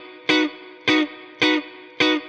DD_StratChop_105-Bmaj.wav